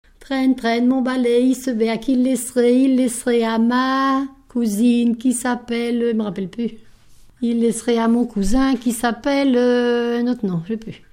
Enfantines - rondes et jeux
Enquête Arexcpo en Vendée-C.C. Saint-Fulgent
Pièce musicale inédite